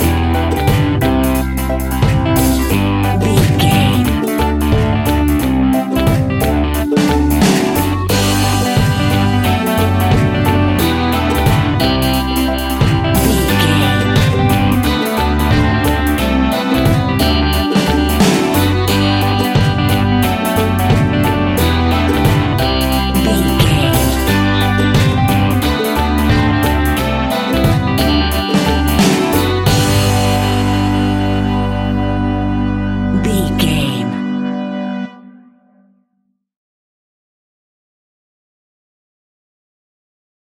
Hot summer reggae music from Barbados!
Ionian/Major
laid back
chilled
off beat
drums
skank guitar
hammond organ
percussion
horns